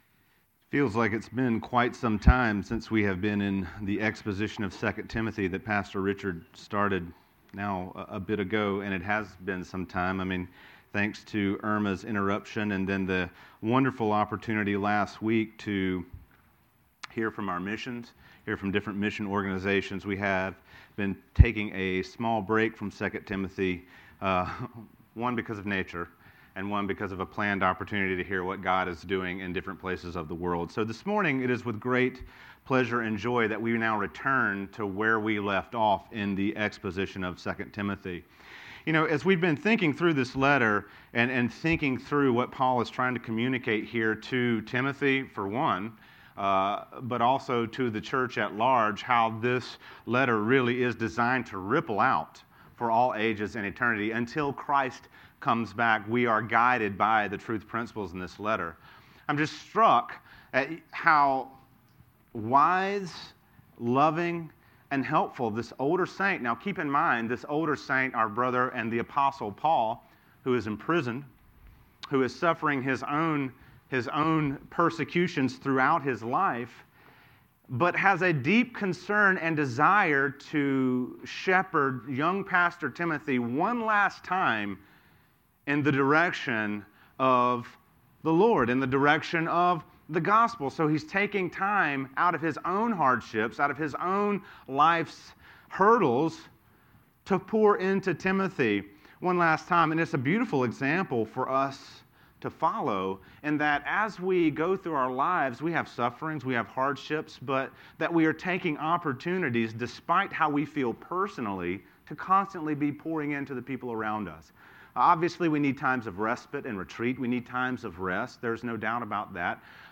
teaches from the series: 2 Timothy, in the book of 2 Timothy, verses 3:1 - 3:9